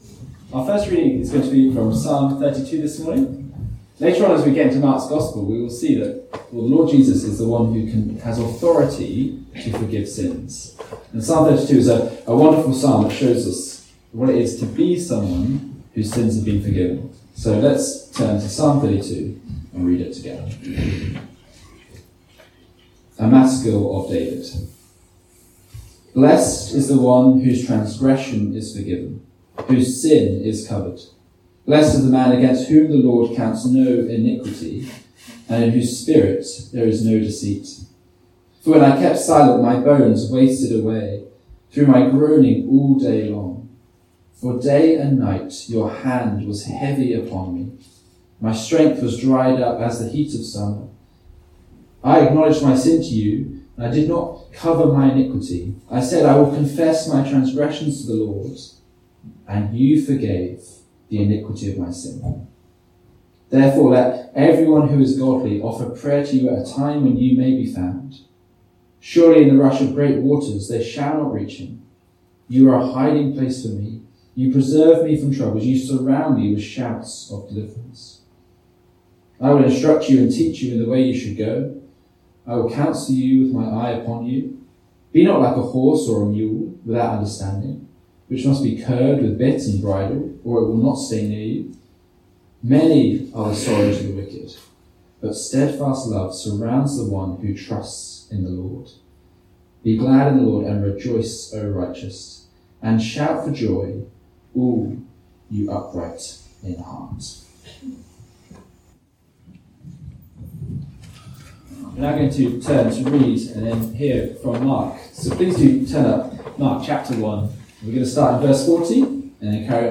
A link to the video recording of the 11:00am service, and an audio recording of the sermon.